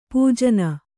♪ pūjana